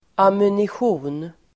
Uttal: [amunisj'o:n]